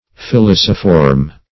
Filiciform \Fi*lic"i*form\